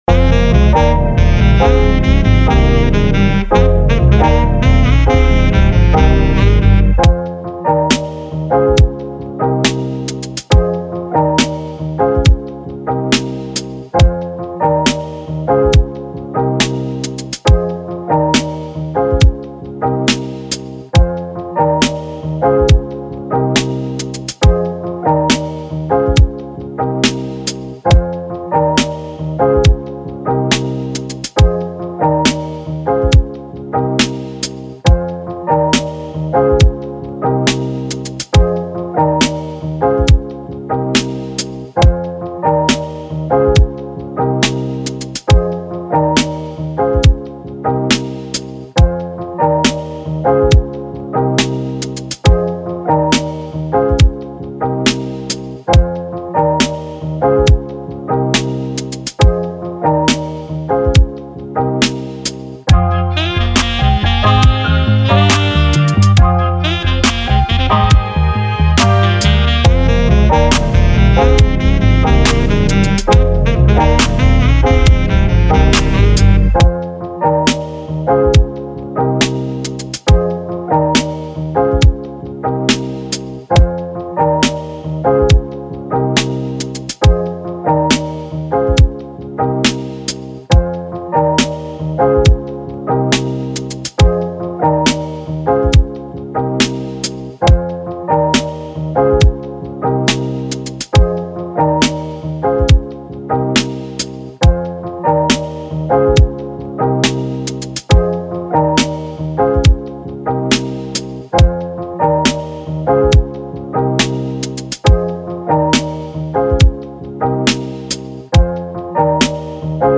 138 Bpm Cminor